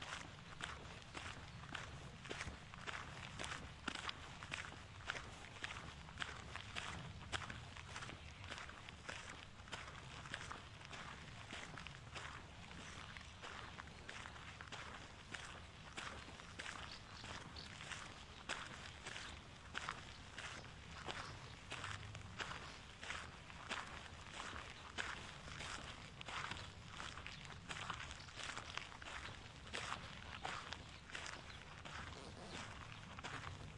秘鲁库斯科纪录片 " 穿着厚重的靴子在泥土上行走，风很大
描述：这是我在风暴来临的村庄里散步。固体污垢土壤。
标签： 脚步声 碎石 步骤 足迹 靴子 污垢 散步 弗利
声道立体声